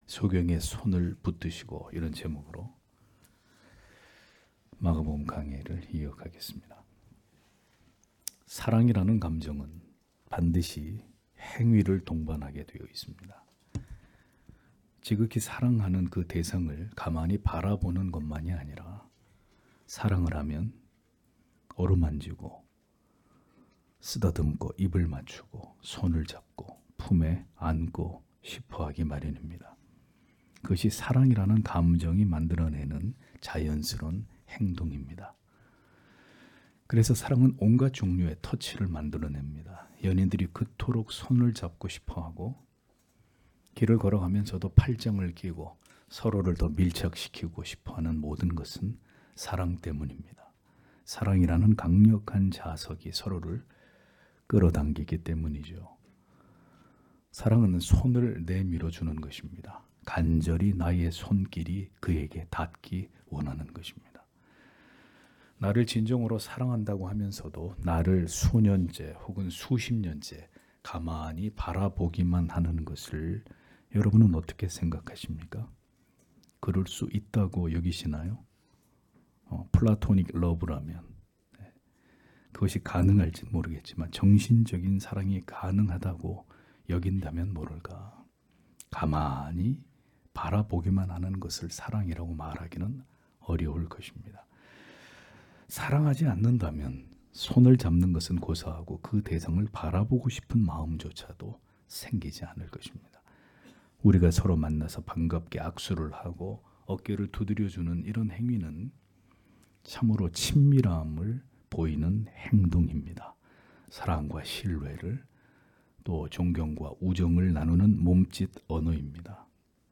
주일오전예배 - [마가복음 강해 31] 소경의 손을 붙드시고 (막 8장 22-26절)